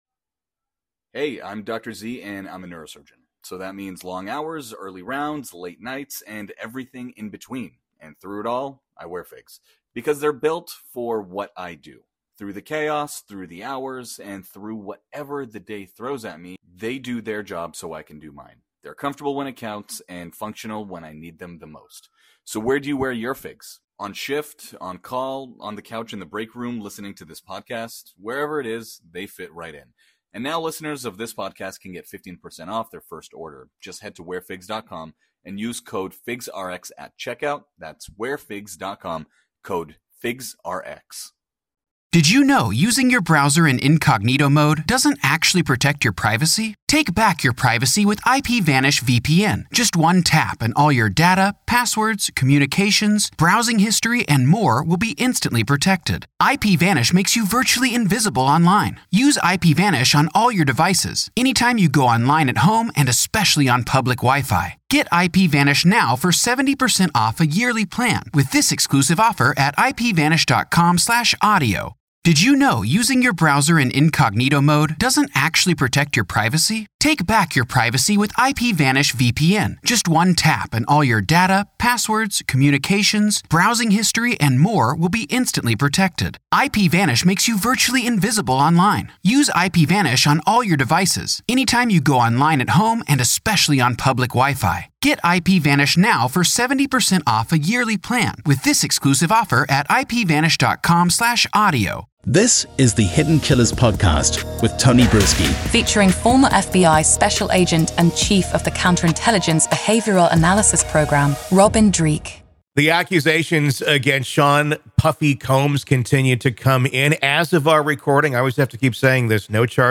Speculation Station: The pair engage in some speculative guesswork about what might have been found during the raids, reminding listeners that in the world of high-profile investigations, the plot is often thicker than a mystery novel.